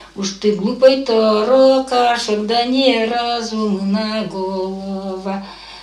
Отличные от нормативных окончания в именительном падеже единственного числа мужского рода прилагательных – безударная флексия –ый в соответствии с литературной -ой
/а-бо-гаа”-той роо-схваа”-ста-лсэ зо-зо-ло-тооо”й ка-зно:”й/